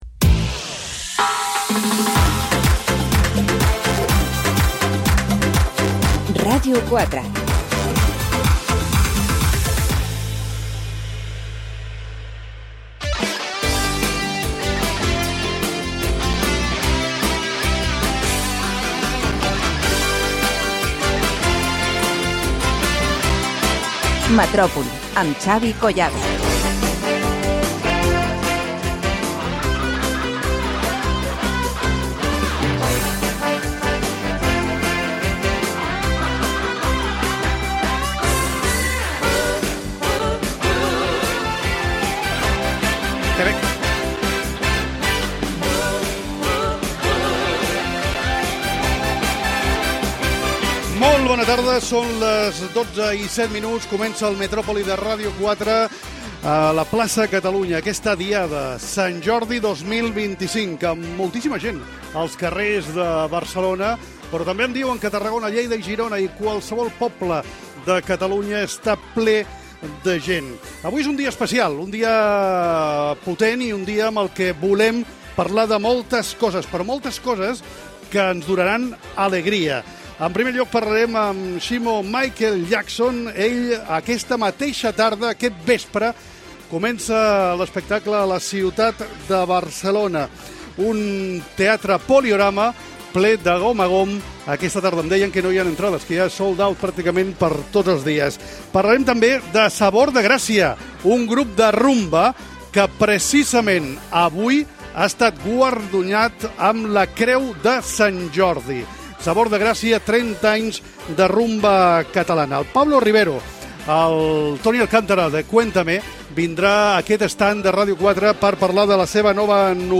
3f99b70853b5c80ea9a9f2b5389c2cc1d4aef03e.mp3 Títol Ràdio 4 Emissora Ràdio 4 Cadena RNE Titularitat Pública estatal Nom programa Metropoli Descripció Programa especial fet des de la Plaça de Catalunya de Barcelona el dia de Sant Jordi.